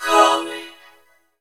COMIN VOX -R.wav